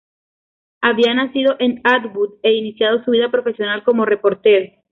re‧por‧te‧ro
/repoɾˈteɾo/